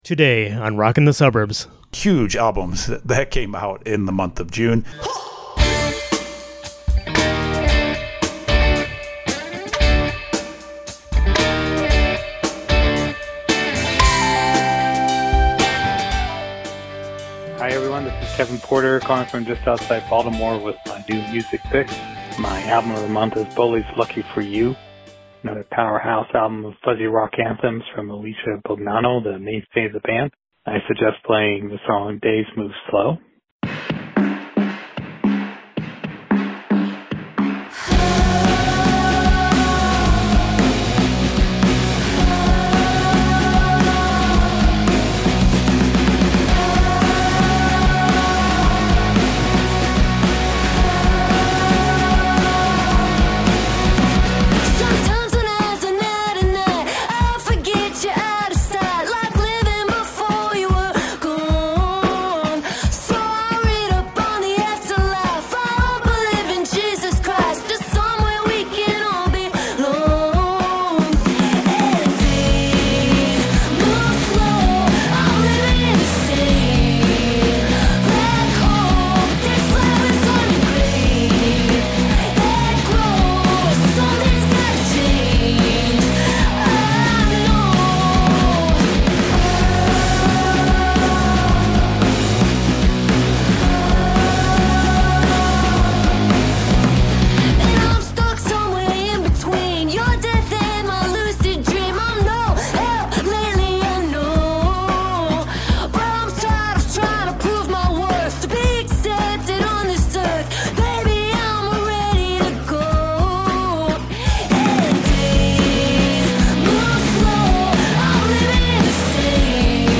Guitars rule on new music picks